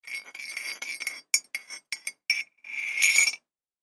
دانلود آهنگ آب 71 از افکت صوتی طبیعت و محیط
دانلود صدای آب 71 از ساعد نیوز با لینک مستقیم و کیفیت بالا
جلوه های صوتی